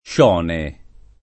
Scione [ + š 0 ne ]